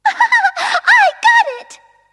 One of Princess Peach's voice clips in Mario Party 5